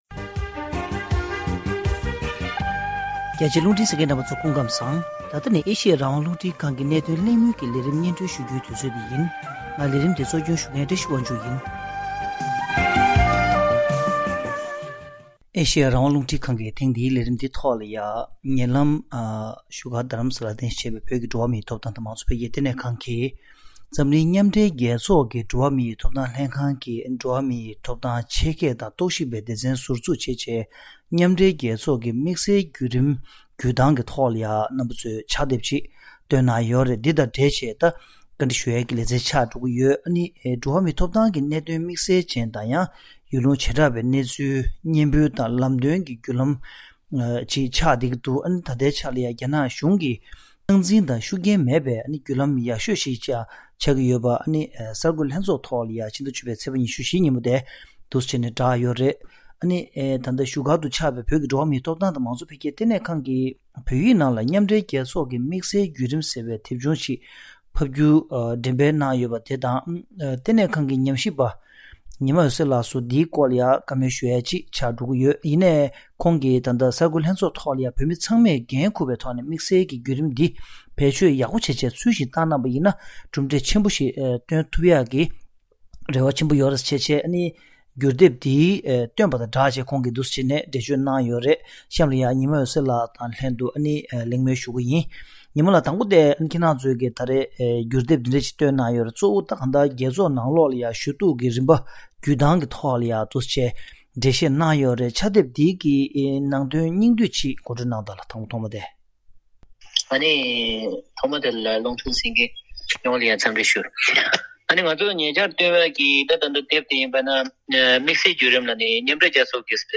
འདི་དང་འབྲེལ་ནས་ཐེངས་འདིའི་གནད་དོན་གླེང་མོལ་གྱི་ལས་རིམ་ནང་།